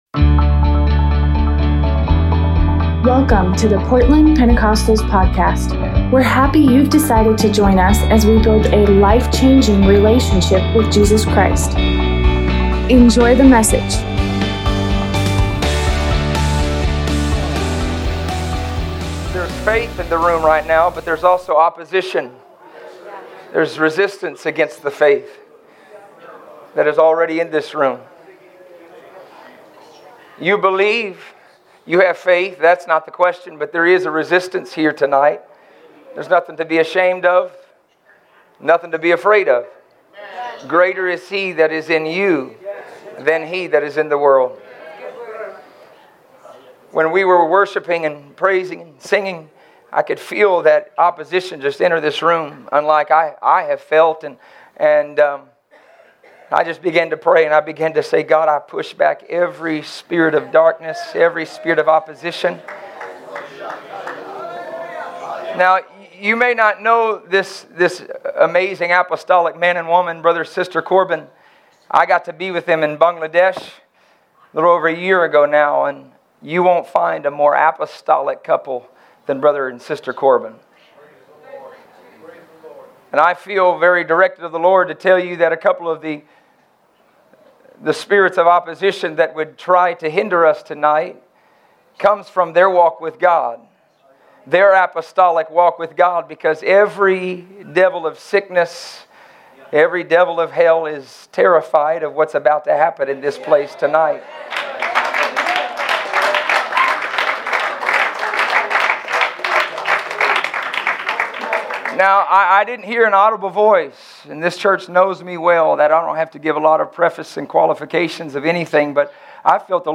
Tuesday night sermon